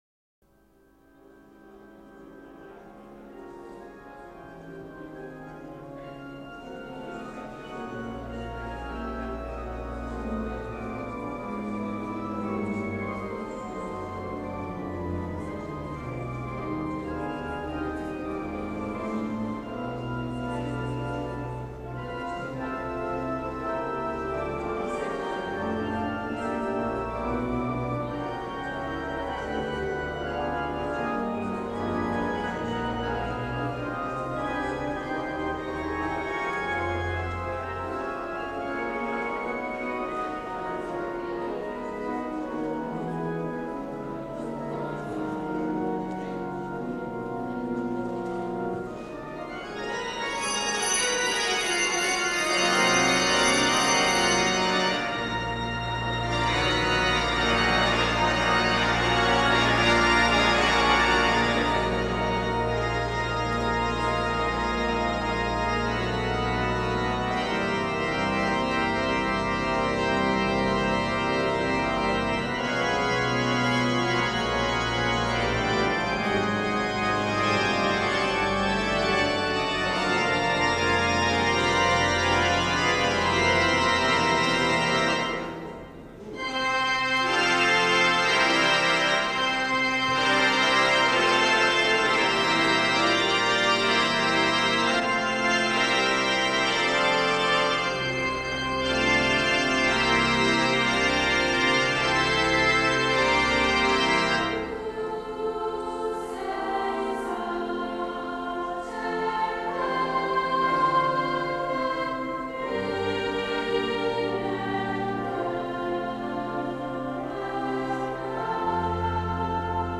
Bergamo, 10 ottobre 1993
Messa Solenne
S. Alessandro in Colonna